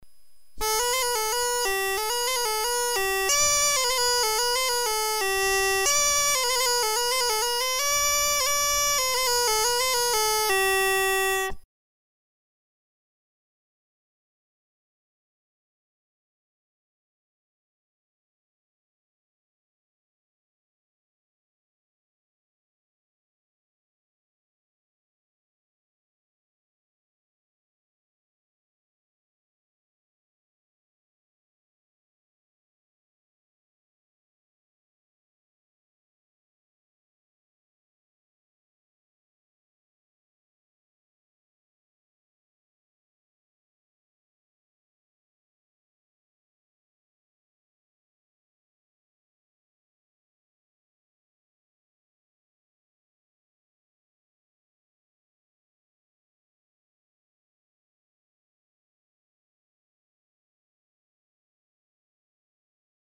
URM Sonos de Sardigna : strumenti musicali antichi - Bena semplice